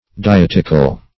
dietical - definition of dietical - synonyms, pronunciation, spelling from Free Dictionary Search Result for " dietical" : The Collaborative International Dictionary of English v.0.48: Dietical \Di*et"ic*al\, a. Dietetic.